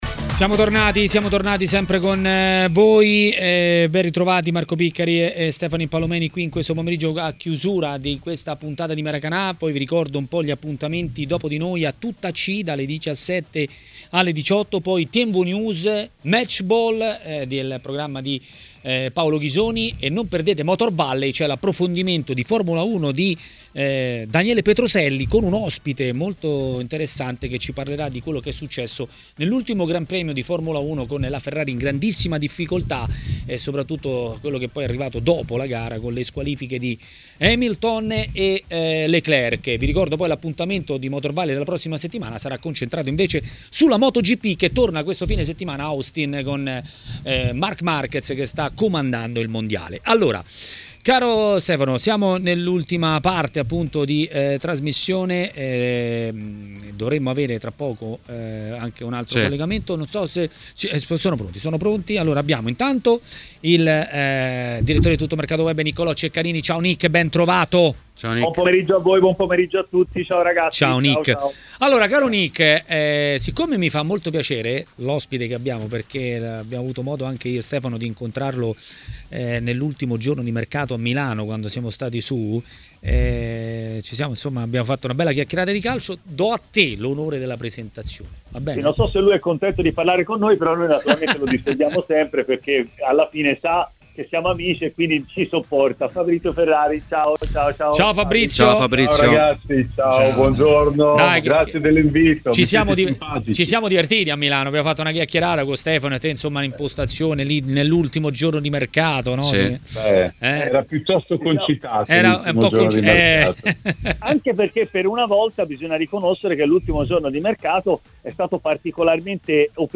Attualità, interviste e tante bufere.